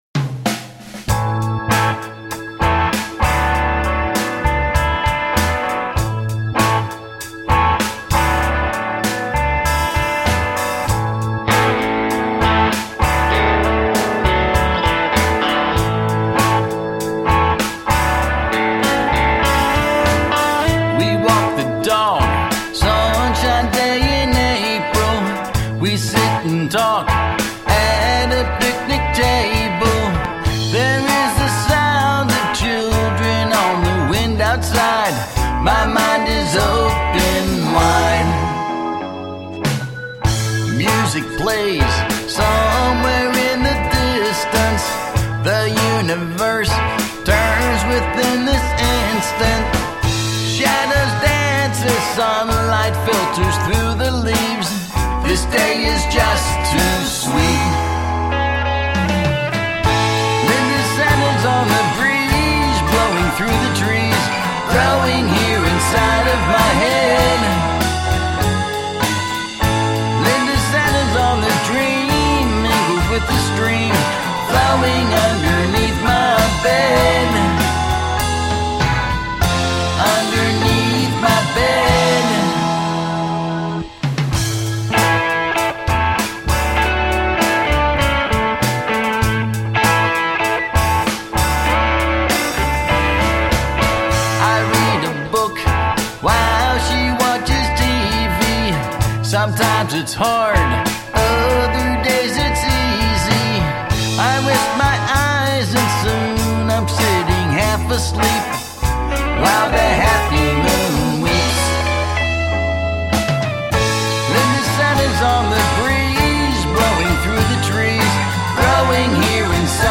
Roots rock meets contemporary folk.
Tagged as: Alt Rock, Folk